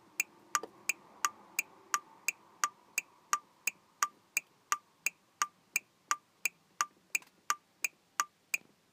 ウインカーブザー取り付け
音量も調整できるのでまぁまぁ良いです。
とりあえず音は鳴りました。
ピコピコ？ペコペコ？